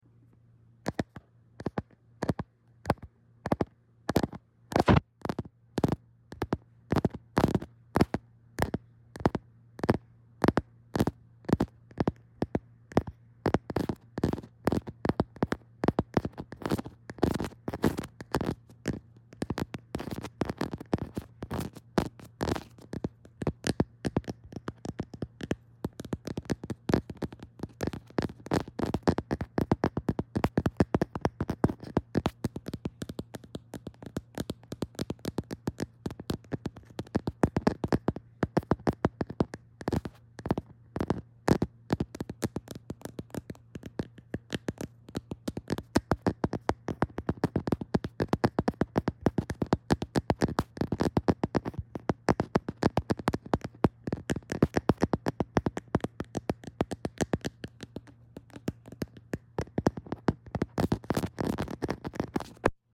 ASMR camer tapping☁🫧 i want sound effects free download